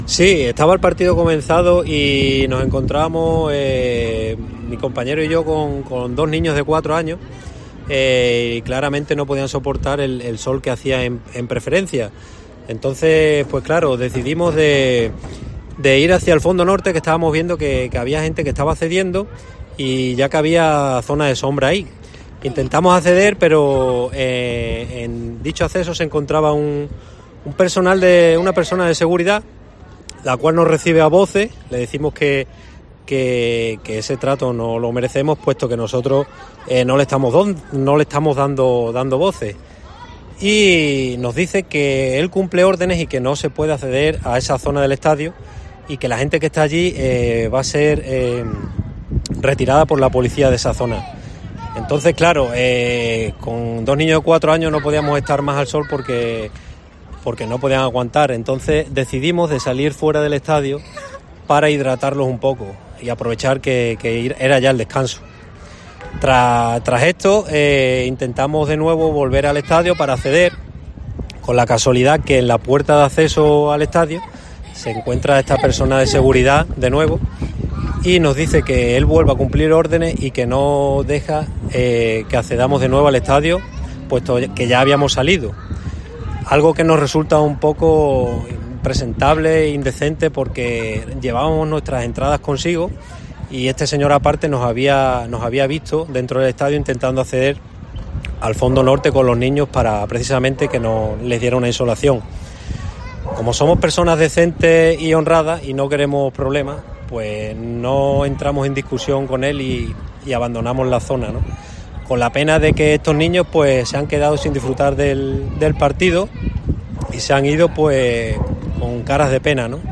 Varios padres y abuelos han explicado a COPE lo ocurrido.